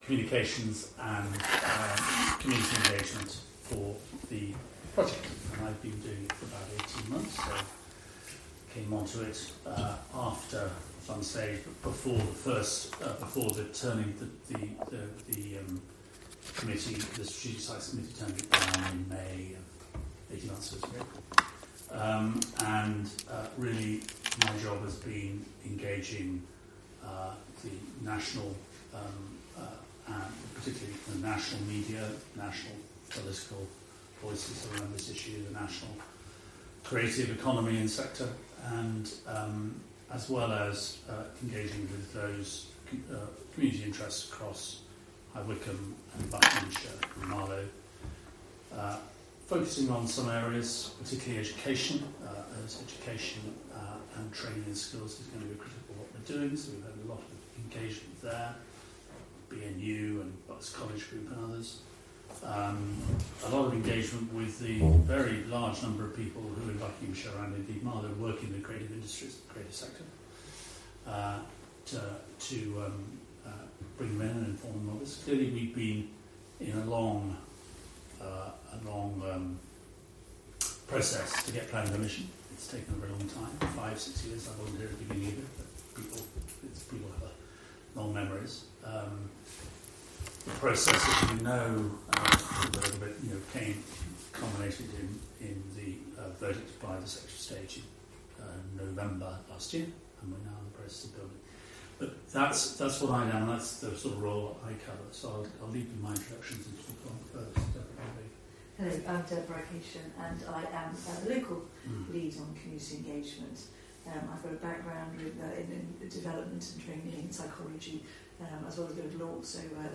Parish Meeting October 17th 2023 6pm
Please note the recording is the official record of the meeting, the transcript is auto generated and may have omissions or errors.